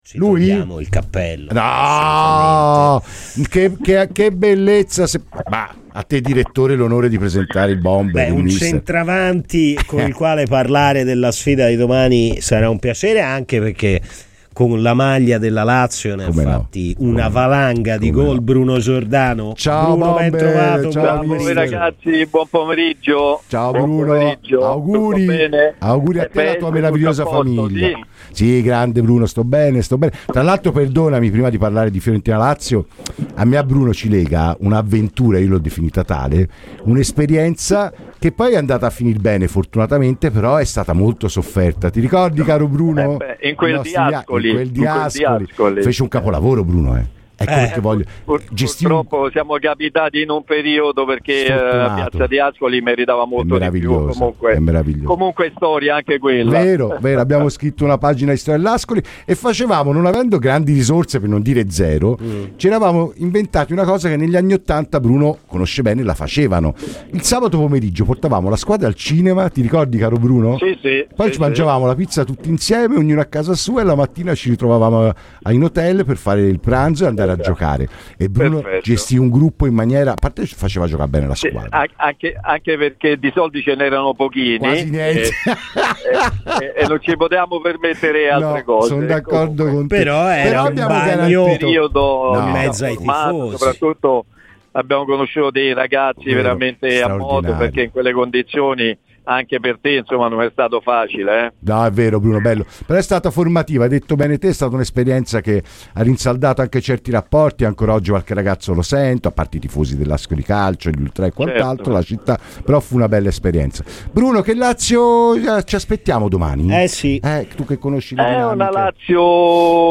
L'ex attaccante della Lazio, Bruno Giordano è intervenuto a Radio FirenzeViola nel corso di "Palla al Centro". Ecco le sue dichiarazioni su Lazio-Fiorentina, gara in programma domani: "In casa Lazio c'è tanto nervosismo.